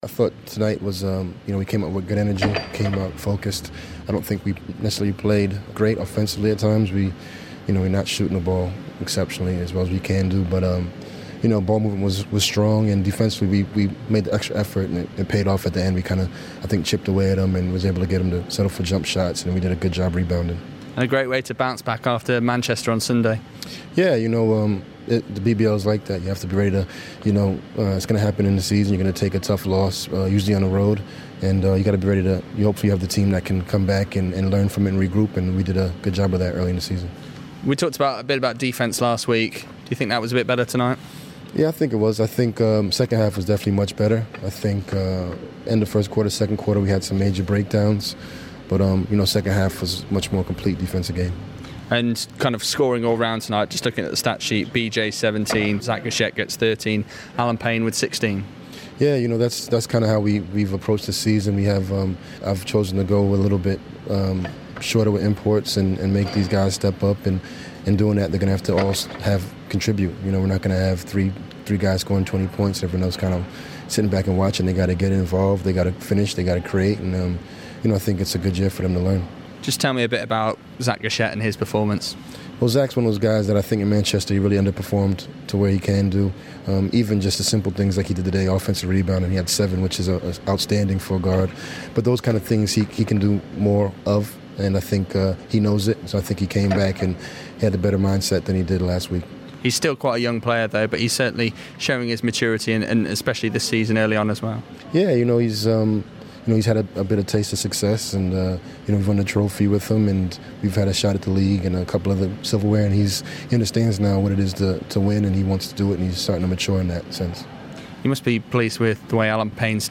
Speaking to BBC Radio Sheffield's
Football Heaven / INTERVIEW